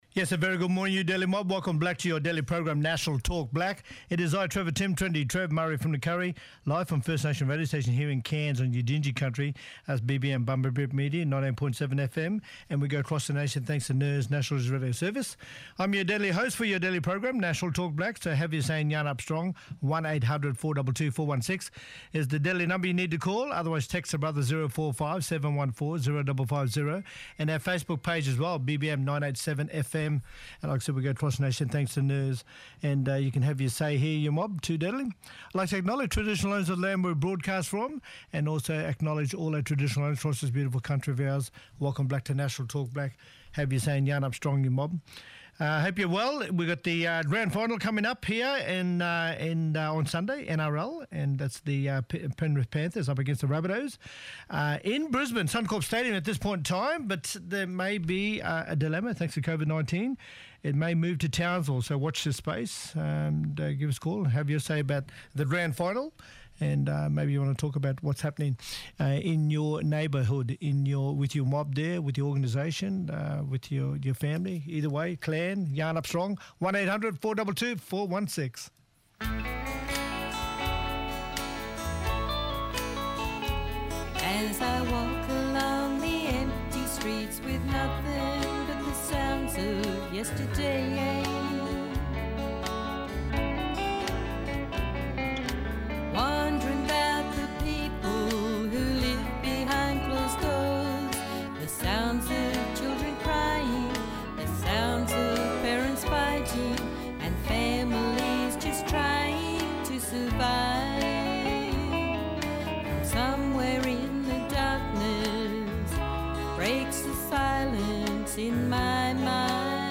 Linda Burney , Shadow Minister for Indigenous Australian’s, talking about how the First Nations vaccination gap has worsened. Vaccination rates for First Nations Australians are falling further behind, not catching up.